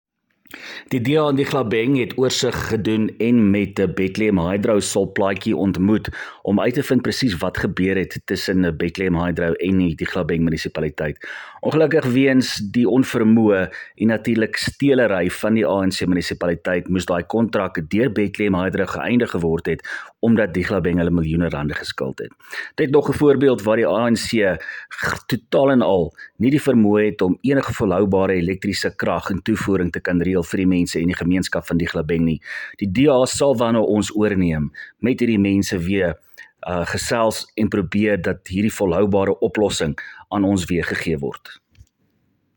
Afrikaans soundbites by Cllr Willie Theunissen.